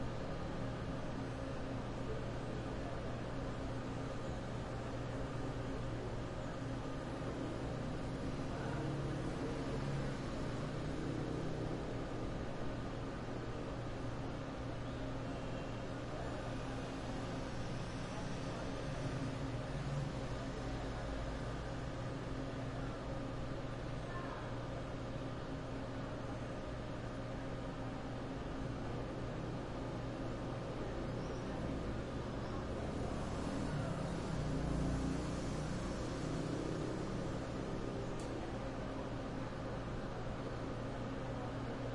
哥伦比亚 " 街道上安静的早晨，小城镇或城市的远处有轻快的交通和鸟类，男人走过时在附近唱歌，狗在追赶。
描述：街道安静的早晨小镇或城市遥远的光交通和鸟类，男子走近唱歌开始，狗追逐街道中间，鸟儿飞走有时蹩脚的翅膀Saravena，哥伦比亚2016
Tag: 西班牙语 街道 上午 宁静